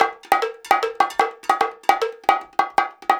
150BONGO 5.wav